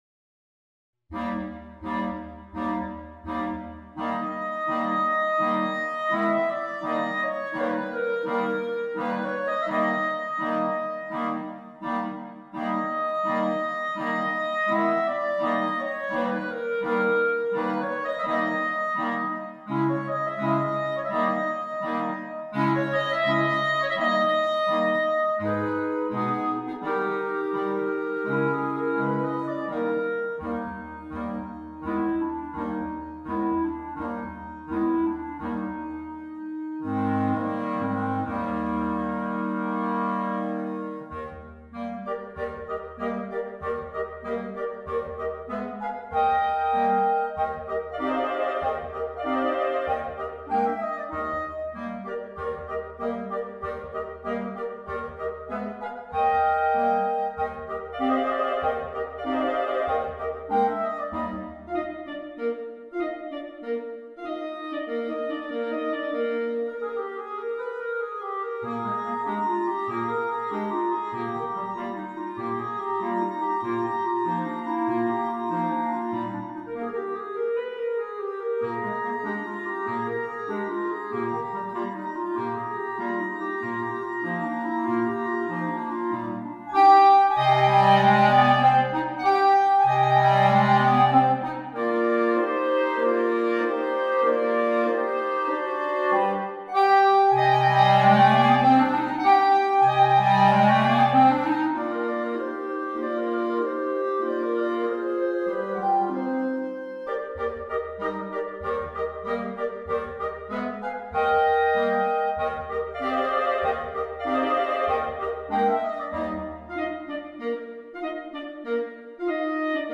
per 5 clarinetti